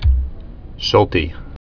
(shōltē), Sir Georg 1912-1997.